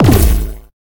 laser.ogg